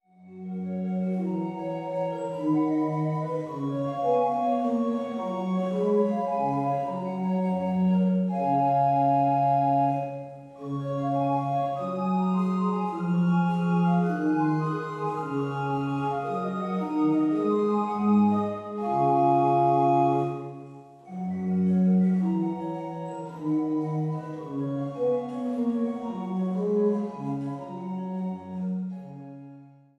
Großengottern, Waltershausen, Altenburg, Eisenach